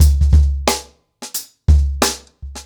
TrackBack-90BPM.1.wav